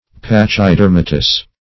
Pachydermatous \Pach`y*der"ma*tous\, a.